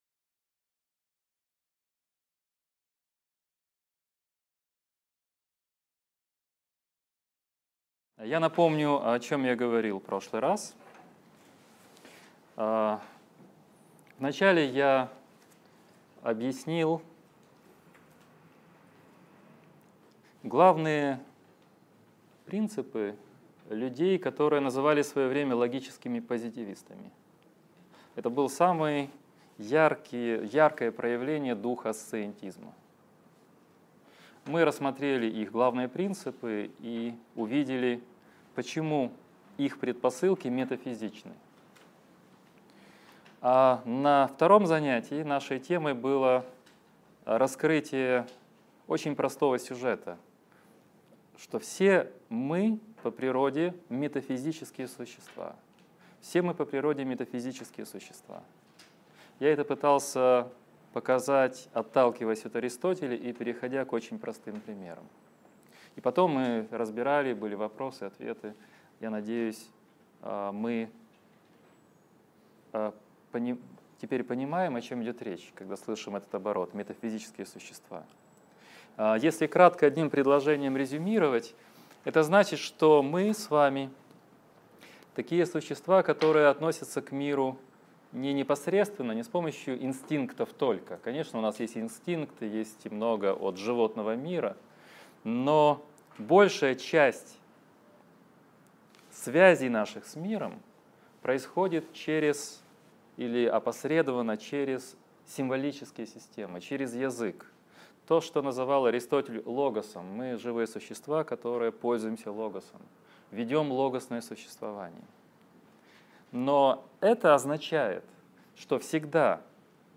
Аудиокнига Лекция 7. Язык и реальность | Библиотека аудиокниг